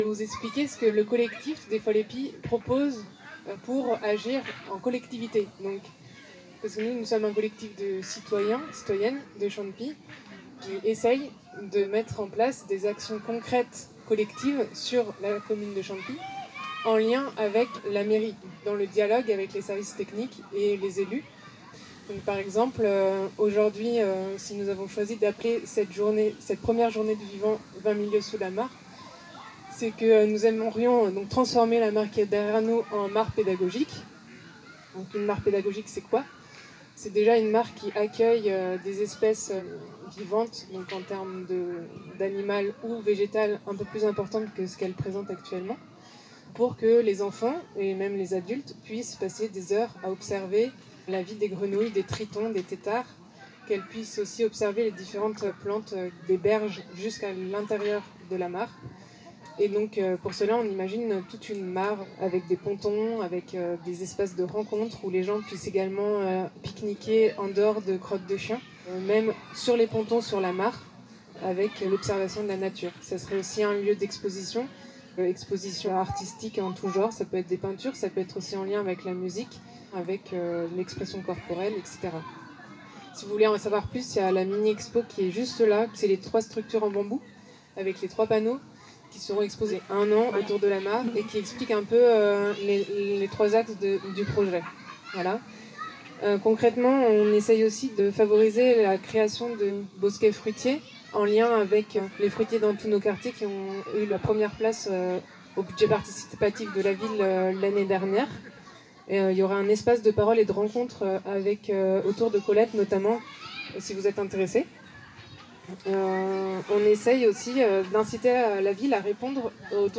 Pour toutes celles et ceux qui n’étaient pas là ce jour-là alors qu’ils auraient aimé, pour toutes celles et ceux qui n’arrivent plus à se souvenir d’un truc qu’ils ont entendu au café-question, voici une deuxième chance car tout a été enregistré !